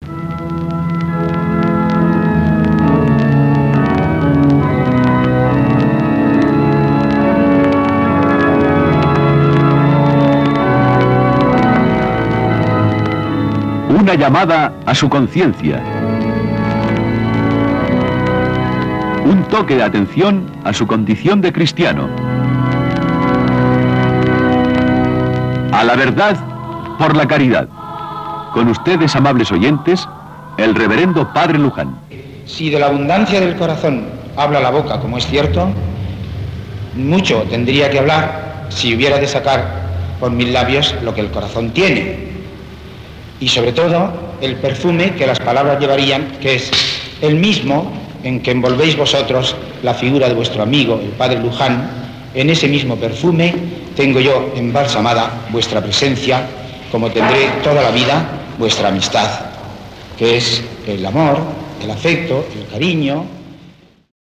Careta del programa
Religió